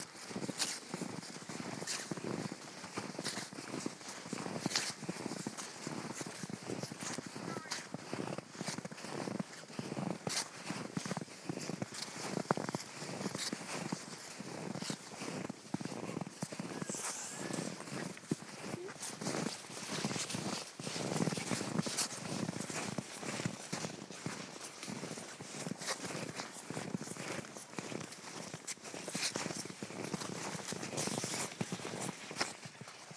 Snow steps